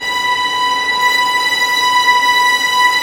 Index of /90_sSampleCDs/Roland - String Master Series/STR_Vlns Bow FX/STR_Vls Pont wh%